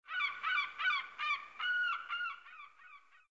SZ_DD_Seagull.ogg